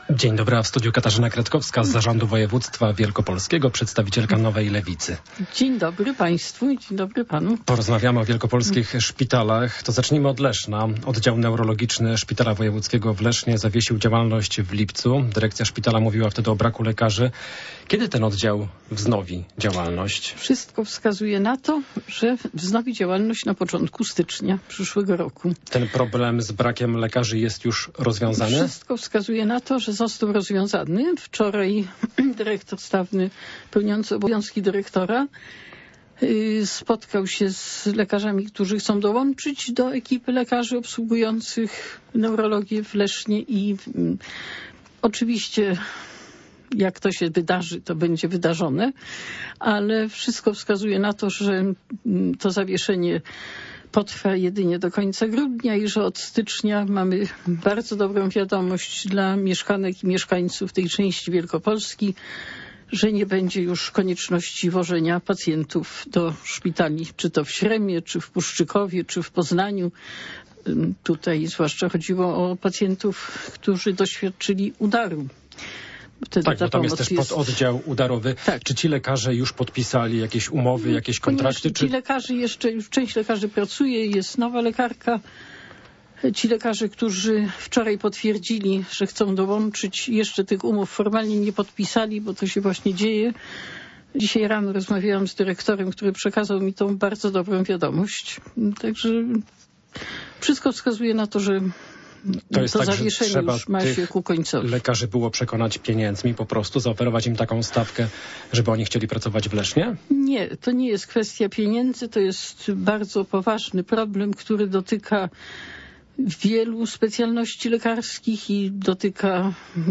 Popołudniowa rozmowa Radia Poznań - Katarzyna Kretkowska